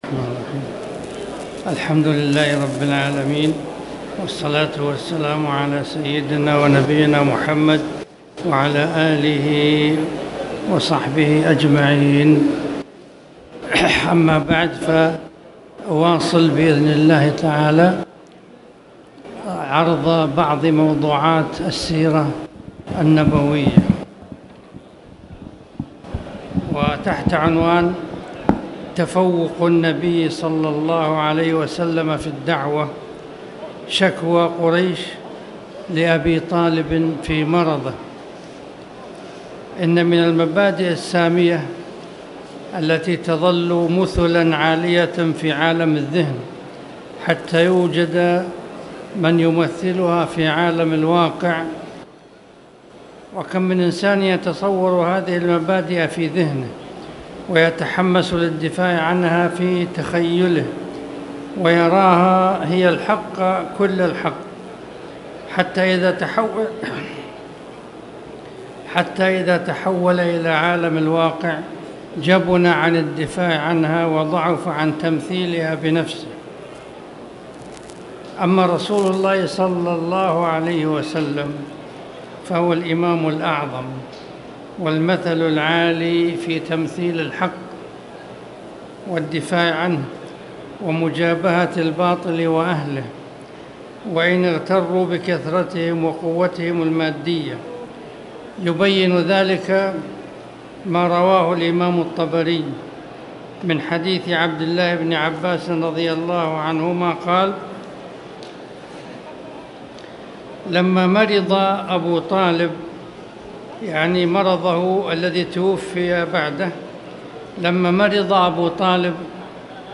تاريخ النشر ٢٤ جمادى الآخرة ١٤٣٨ هـ المكان: المسجد الحرام الشيخ